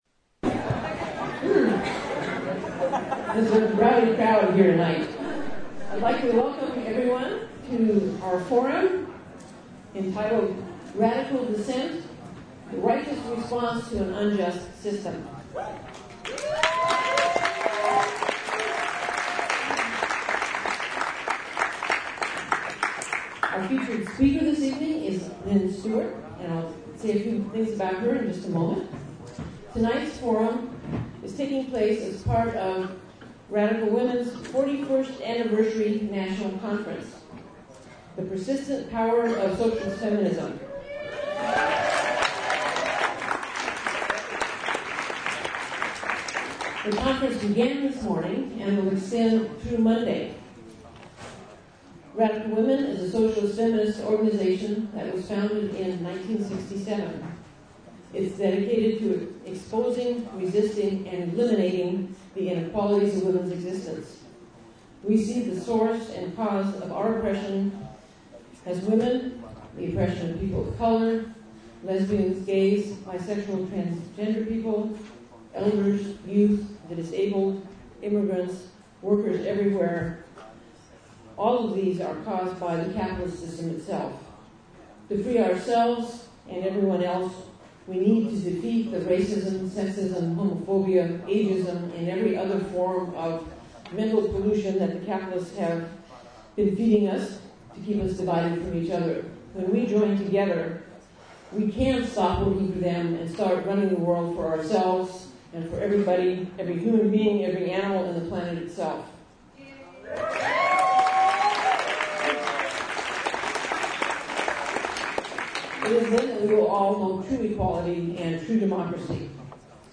Hear Lynne Stewart speak at the annual Radical Women Conference in San Francisco on Octeber 3. She discusses her case, in the context of the need to further challenge some of the criminal justice abuses in the "War on Terror" and work to reverse the overall direction the government is taking the country in, with an emphasis on marxist-based solutions.
Lynne Stewart speaks in San Francisco at Socialist-Feminist Conference
Through all this, she appeared energetic and high-spirited, her New York wit firmly intact.